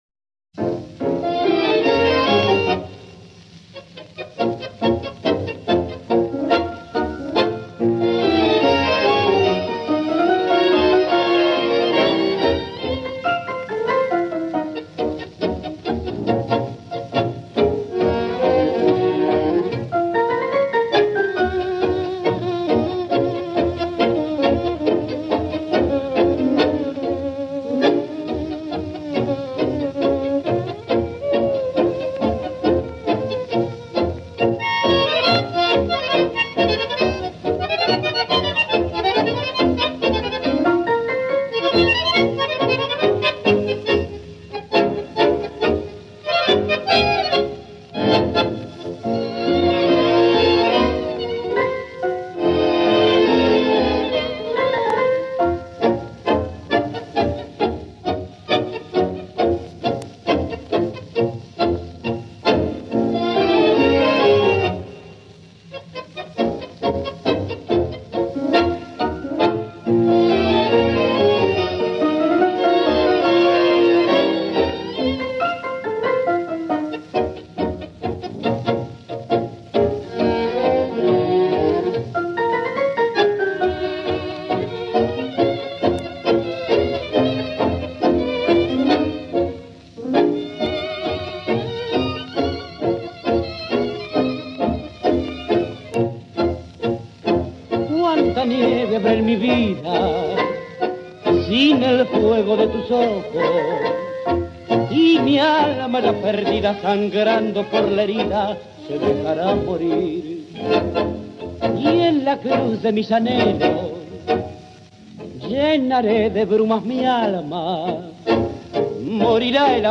very little singing and the orchestra the main attraction